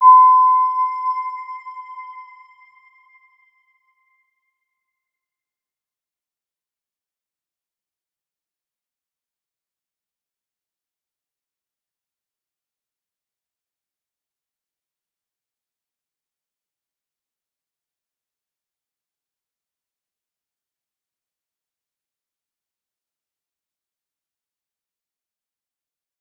Round-Bell-C6-mf.wav